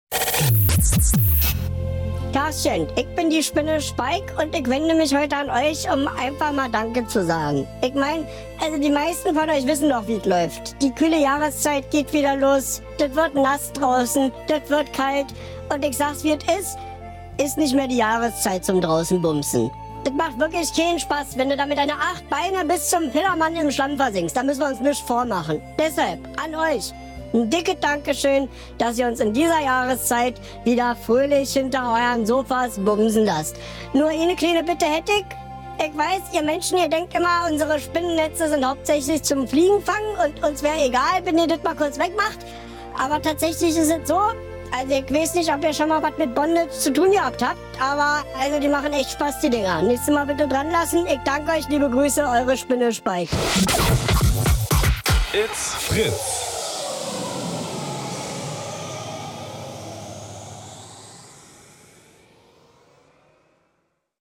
Herbst - Spinnen-Sex im Wohnzimmer | Fritz Sound Meme Jingle